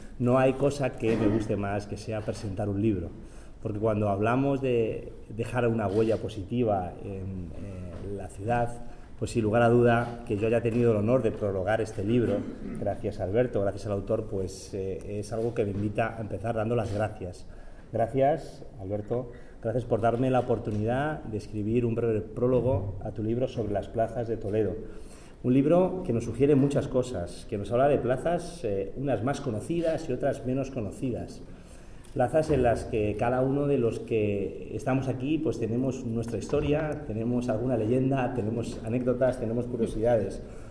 La Sala Capitular acoge la presentación del libro
velazquez-presentacion-libro-plazas-de-toledo-.mp3